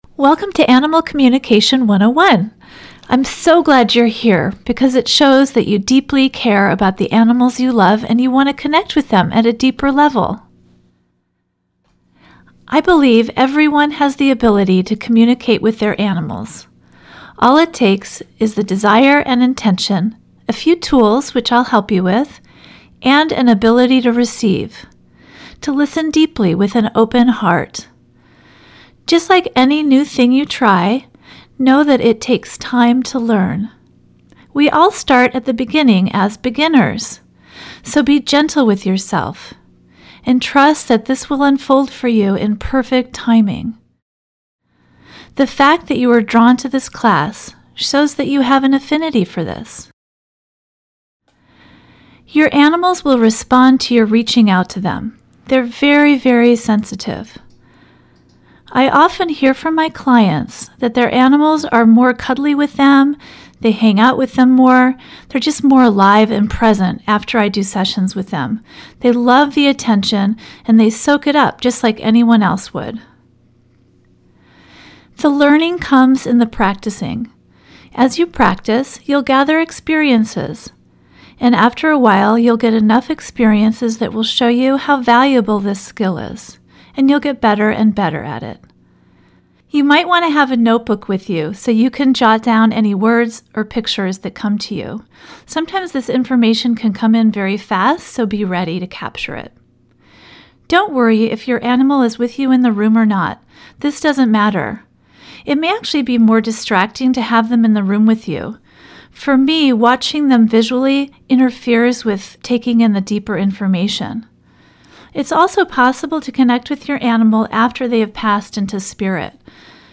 Guided+Meditation:+How+to+Connect+with+Your+Animal